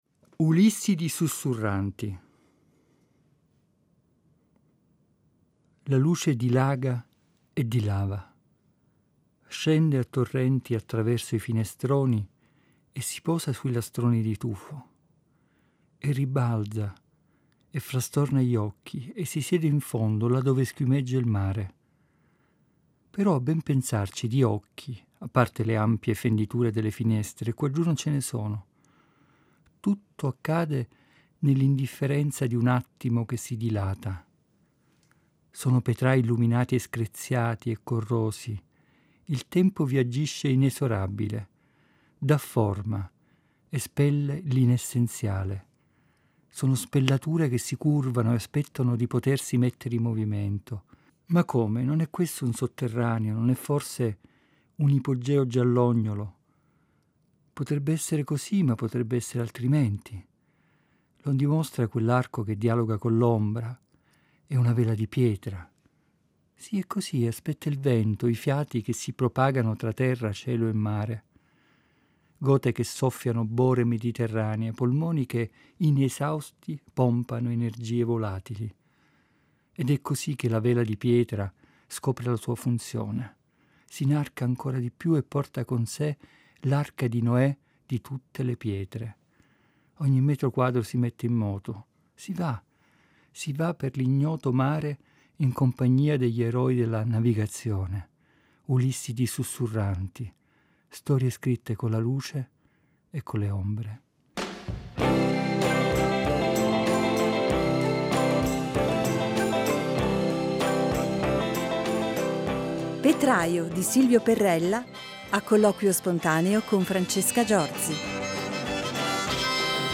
a colloquio spontaneo